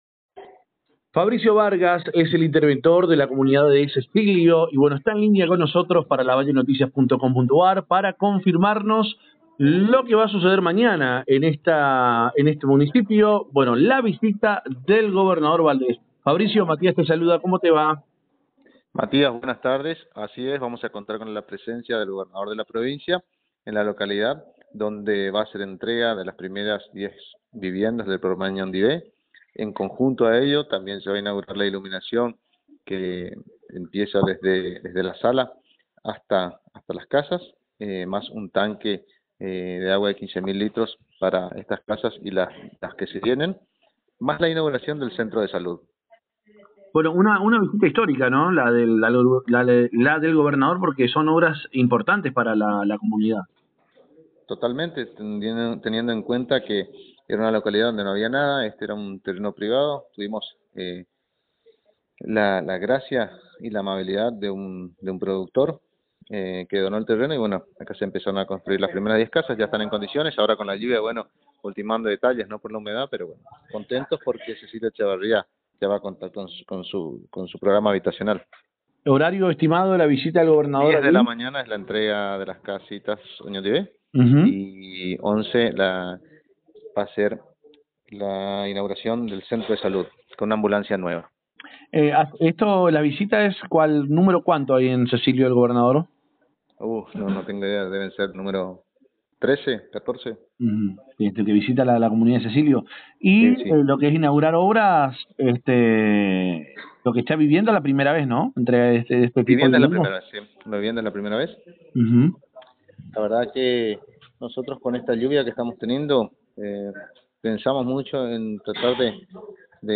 Fabricio Vargas es el interventor de esta joven comuna y en dialogo con nuestro medio confirmo la presencia del mandatario provincial, quien llega a esta zona para la entrega de viviendas del programa «Oñondive» y para la inauguración del Centro de Salud.